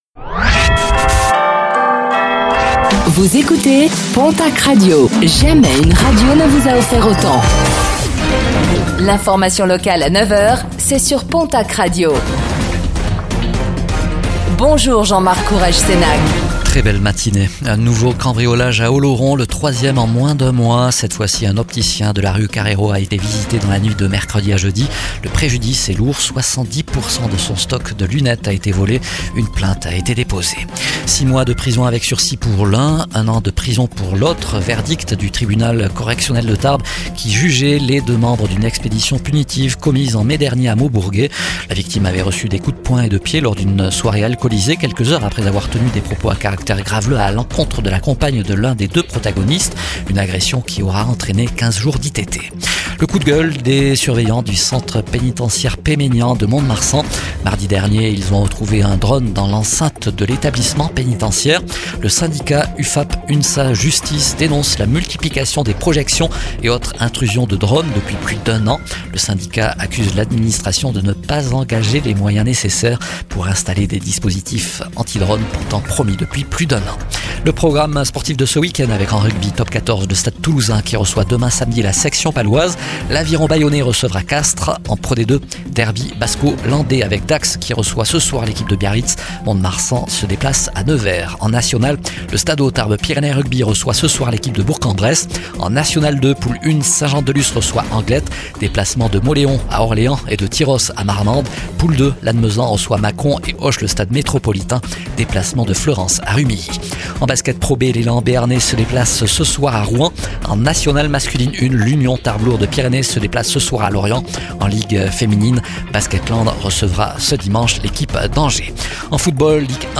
Infos | Vendredi 23 janvier 2026